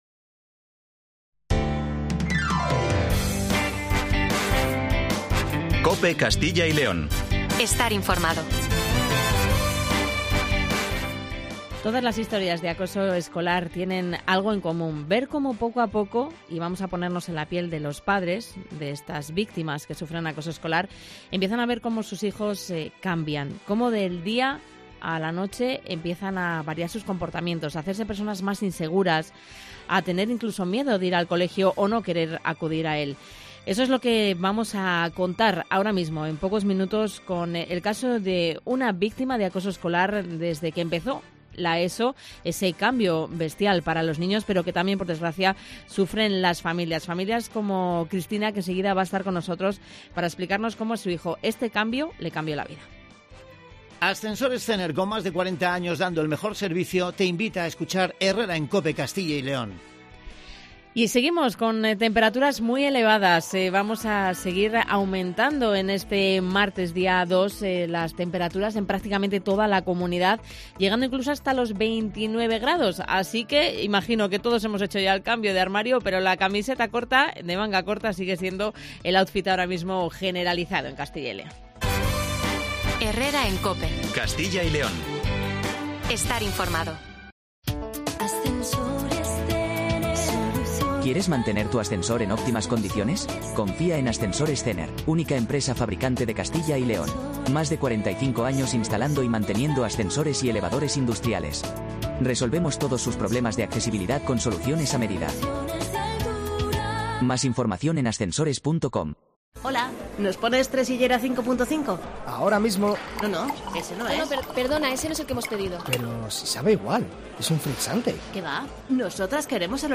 Conocemos el testimonio de la madre de una víctima de acoso escolar que sufrió vejaciones y maltrato físico y que fue denunciado por el propio colegio.